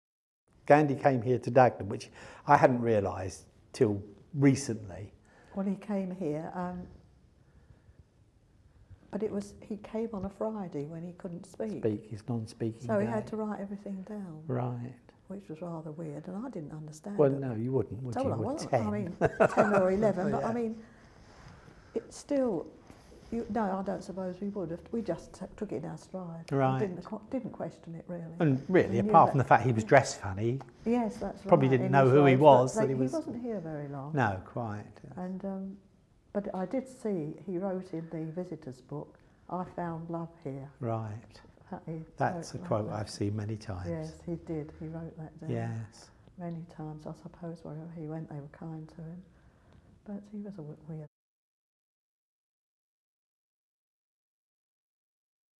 For eye witness account of the visit